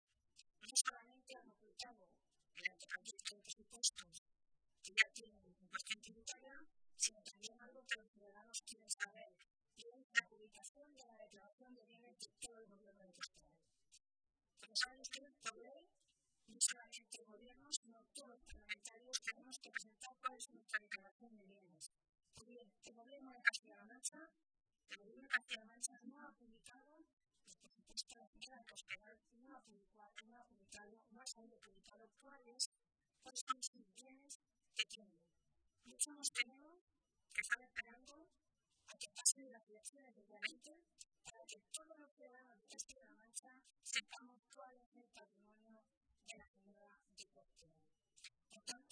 Milagros Tolón, portavoz de Empleo del Grupo Socialista
Cortes de audio de la rueda de prensa